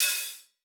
TC2 Live Hihat13.wav